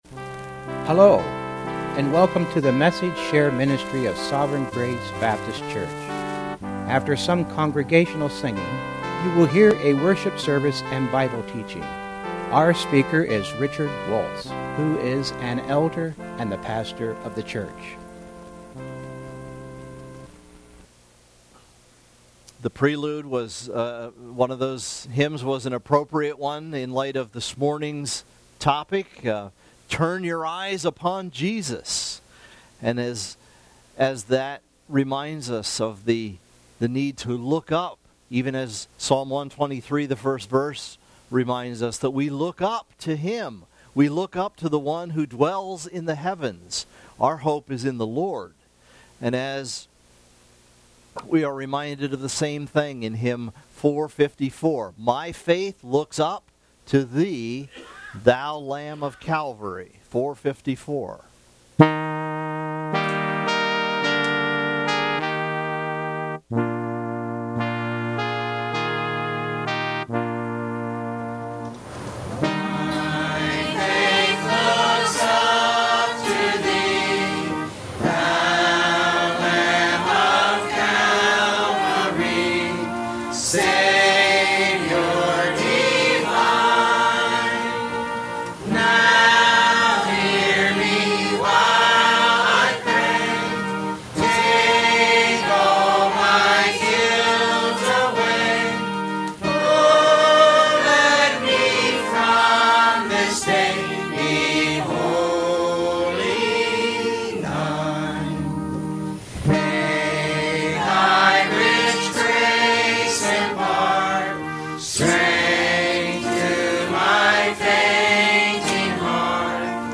Message Details: Lord Have Mercy